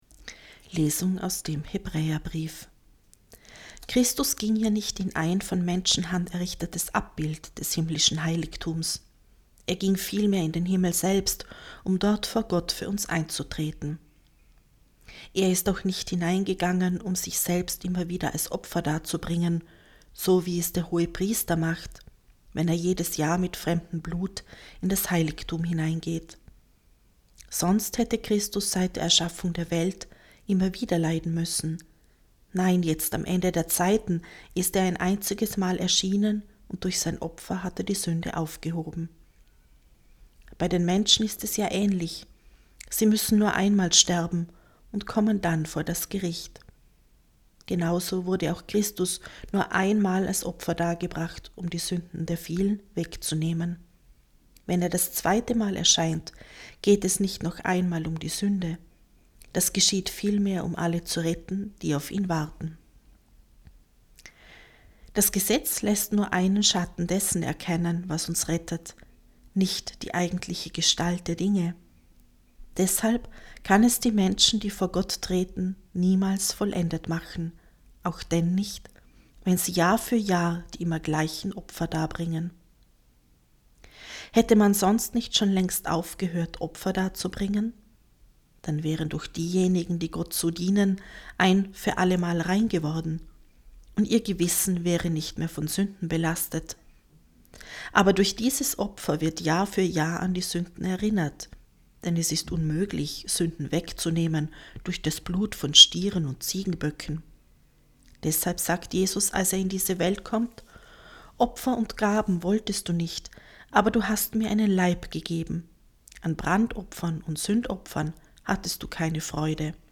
Wenn Sie den Text der 2. Lesung aus dem Hebräerbrief: